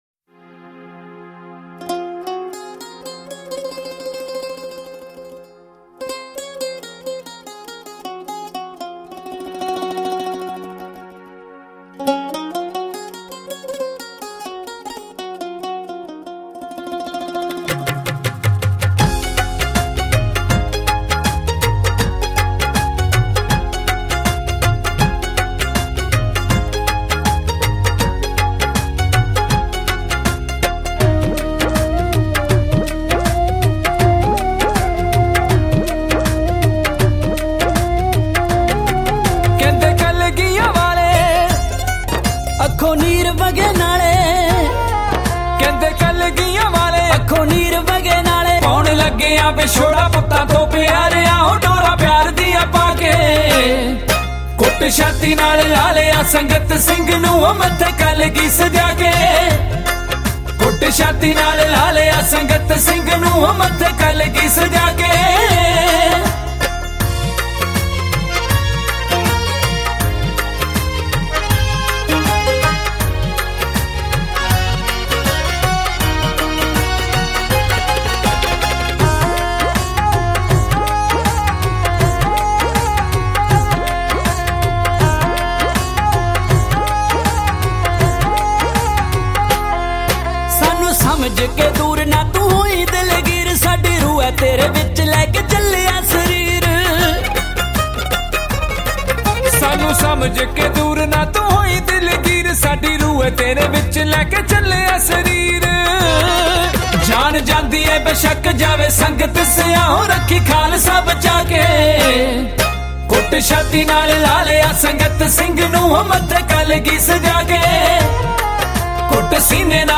Genre: Sikh Song